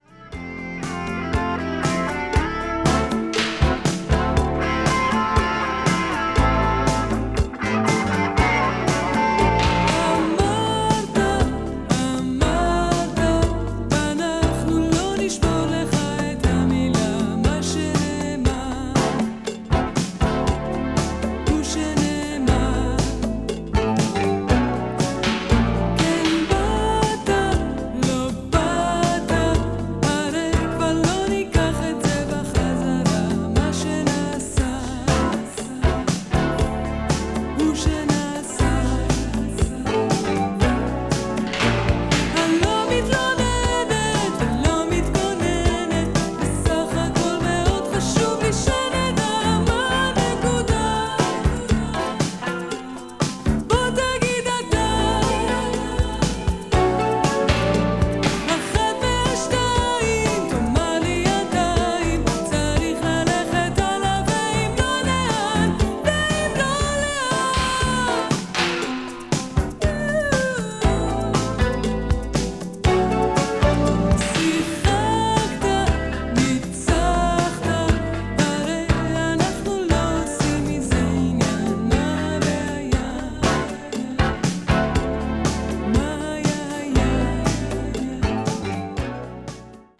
a.o.r.   israeli pop   mellow groove